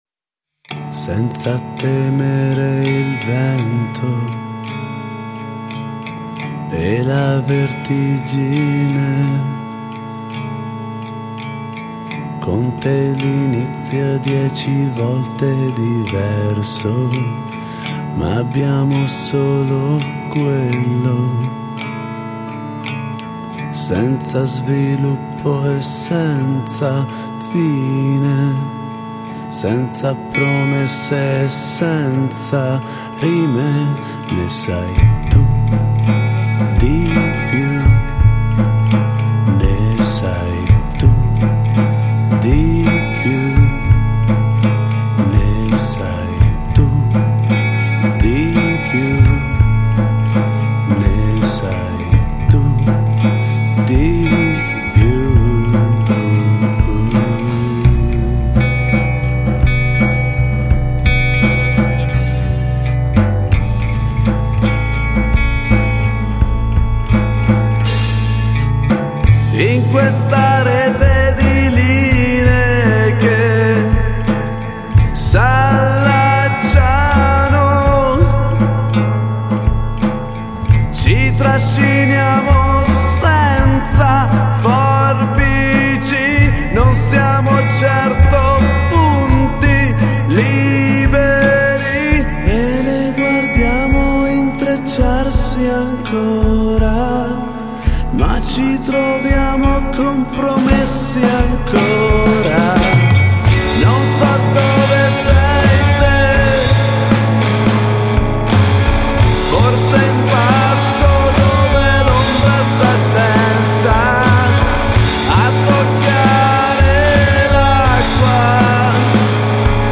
Luogo esecuzioneBluscuro
GenerePop / Musica Leggera
la musica è rarefatta, il canto  rimbalza nel vuoto